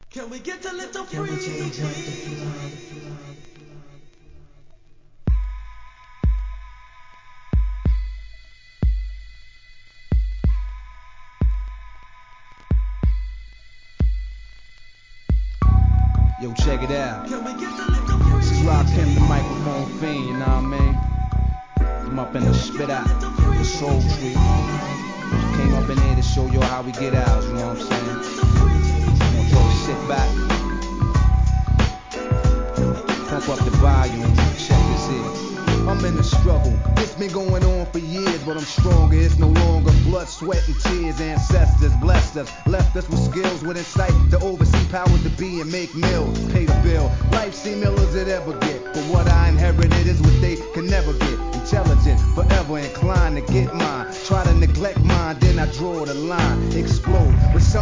HIP HOP/R&B
大人なR&Bばかりを6曲収録したお得盤♪